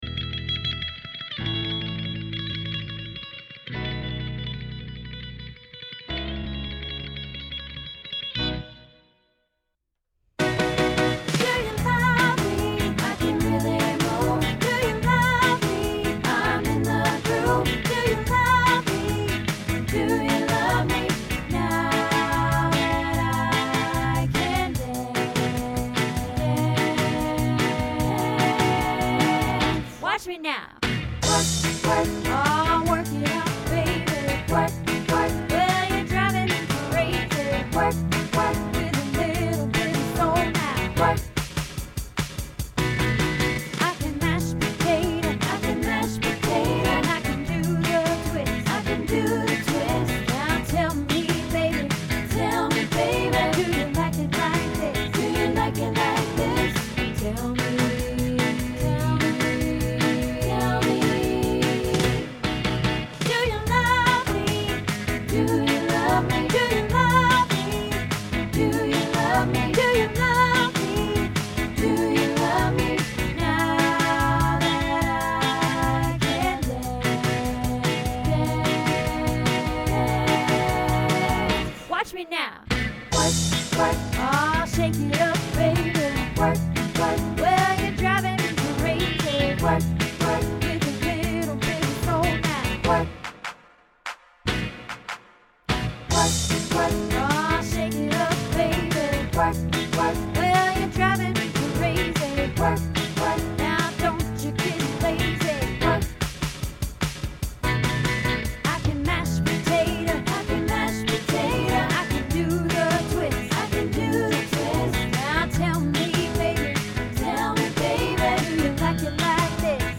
Do You Love Me Practice